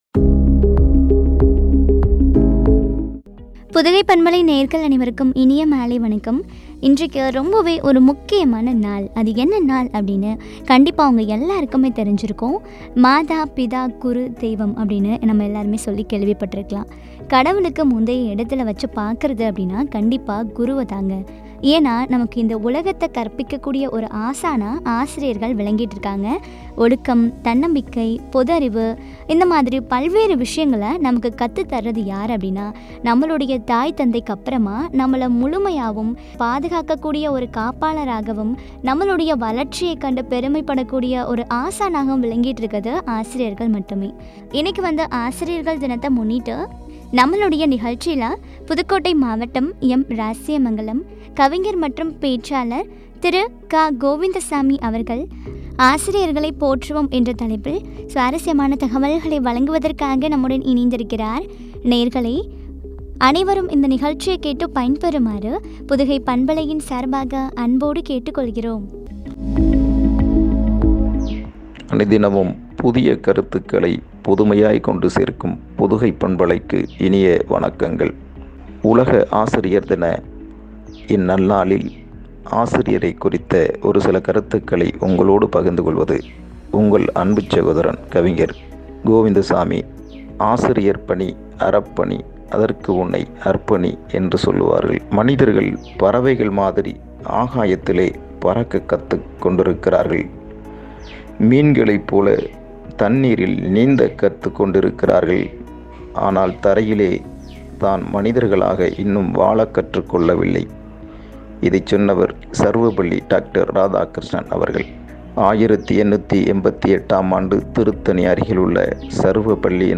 ‘’ஆசிரியர்களைப் போற்றுவோம்’’ குறித்து வழங்கிய உரையாடல்.